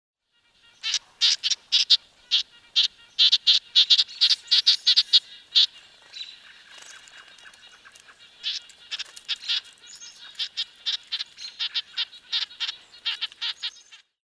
Подборка включает разные варианты голосов, записанных в естественной среде обитания.
Голос пернатой